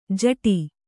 ♪ jaṭi